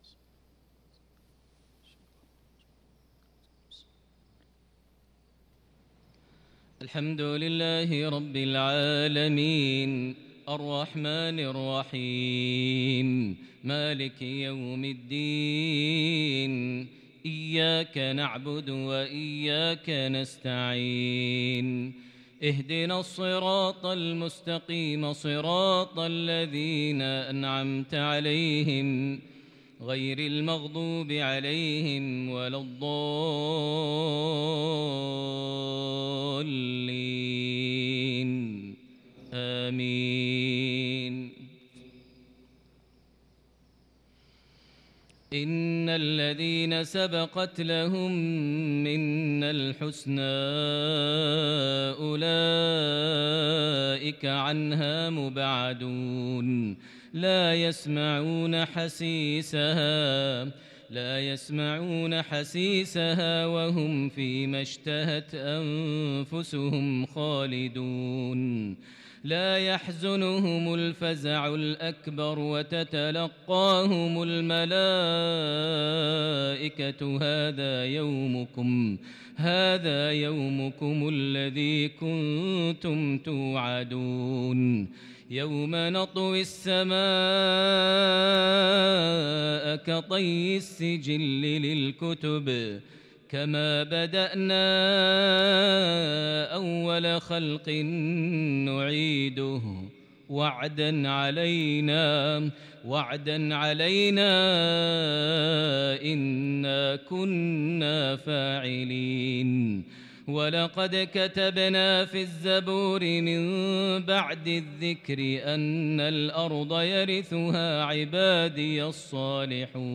صلاة المغرب للقارئ ماهر المعيقلي 2 ربيع الأول 1443 هـ
تِلَاوَات الْحَرَمَيْن .